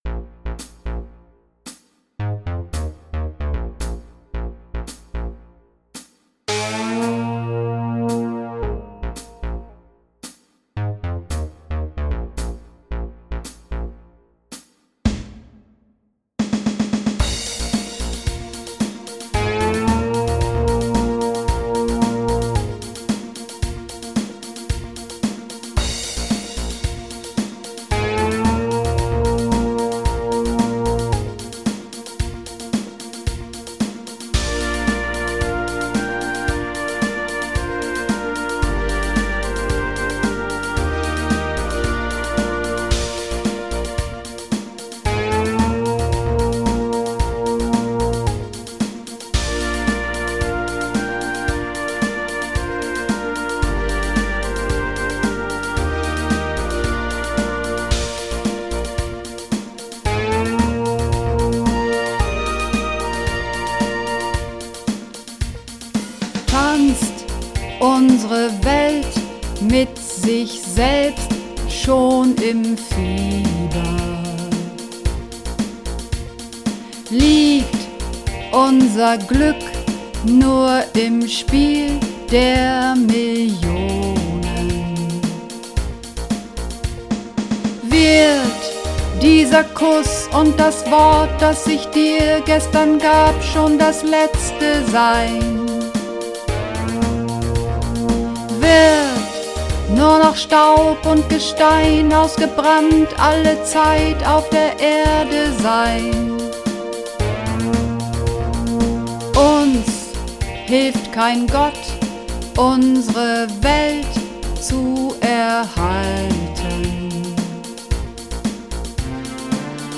Der blaue Planet (Alt)
Der_blaue_Planet__1_Alt.mp3